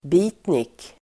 Ladda ner uttalet
Uttal: [b'i:tnik]